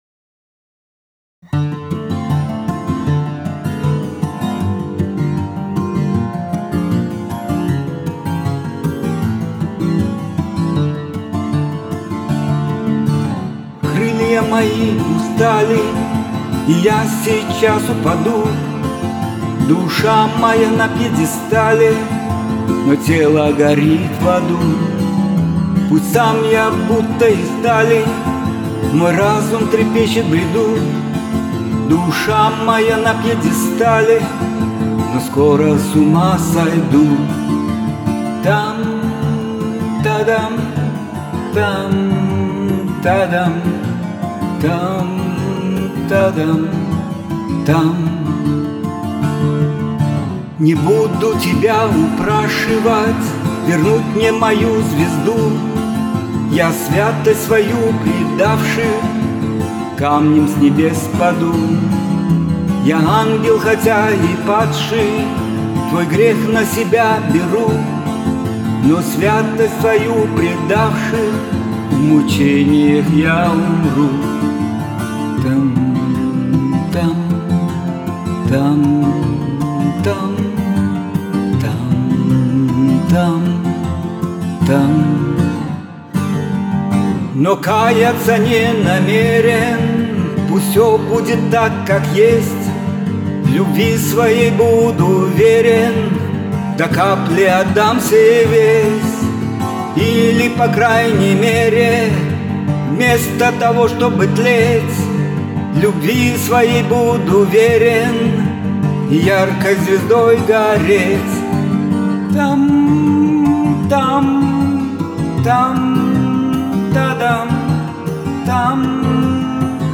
гитара
В мае 2022 года он сделал запись этой песни под гитару.